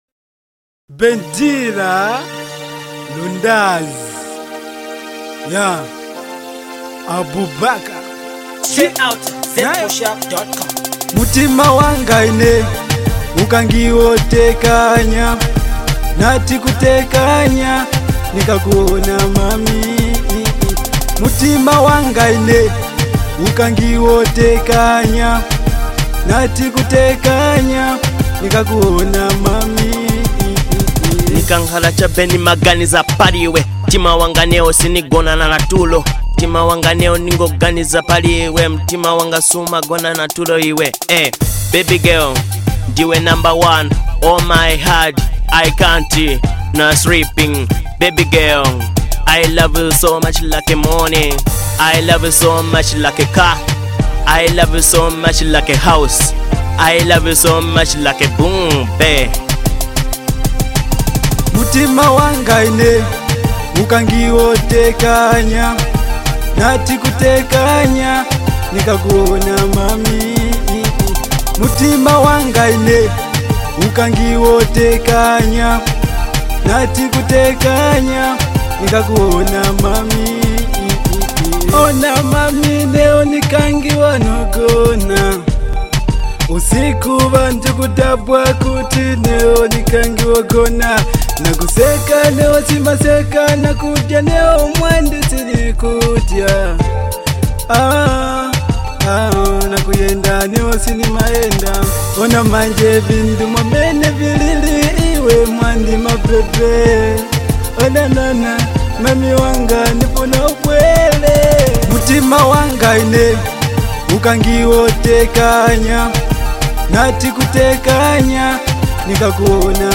Love song